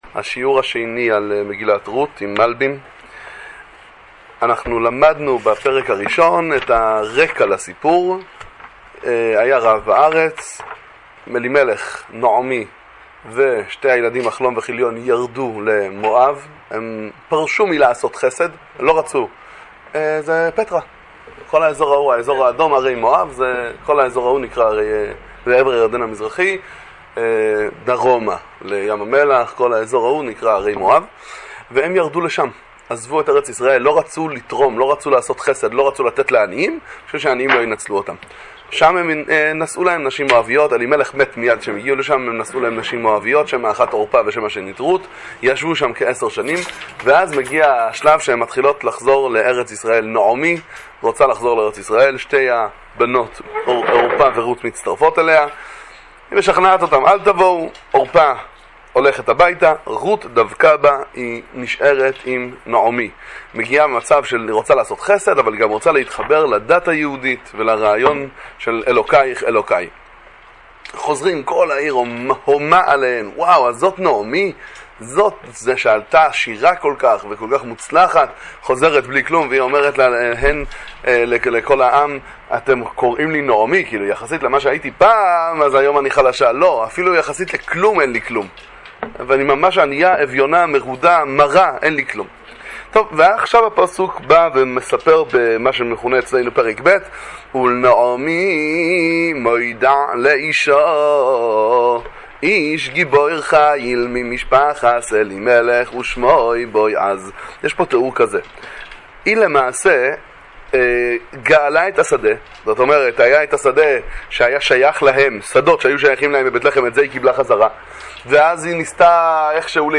שיעור בנביאים וכתובים עם פירוש המלבי"ם, שיעורי תורה לחג השבועות, דברי תורה מגילת רות